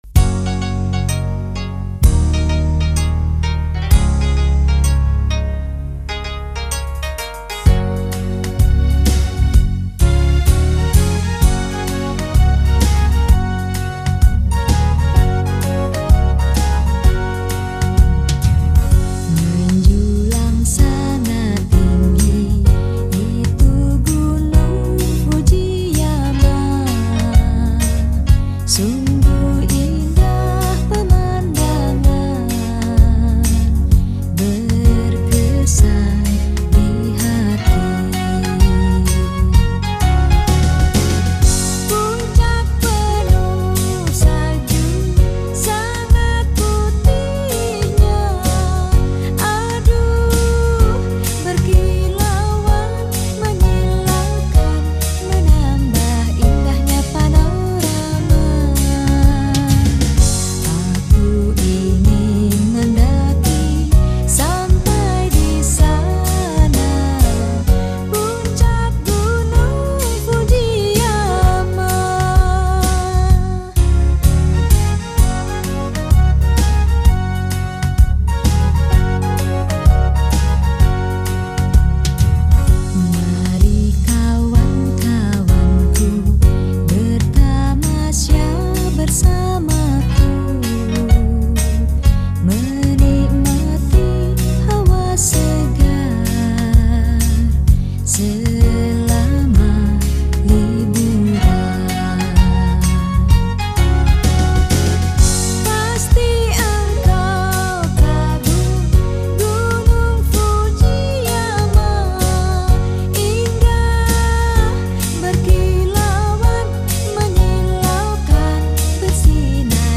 Lagu Pop